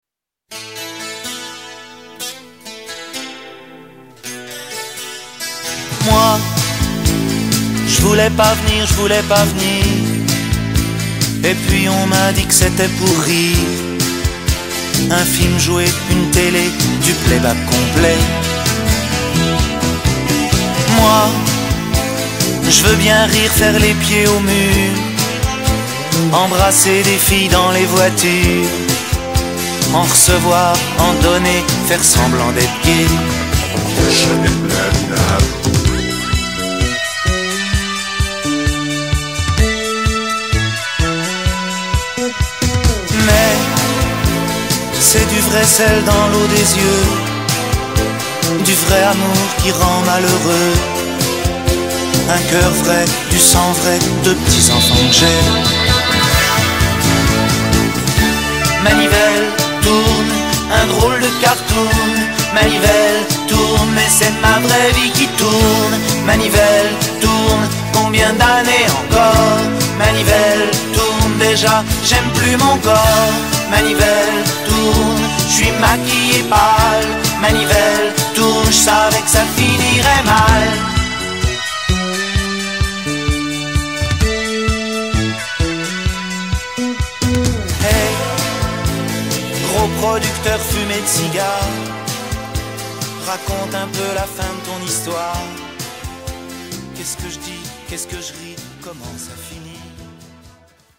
tonalités de DO majeur et LA majeur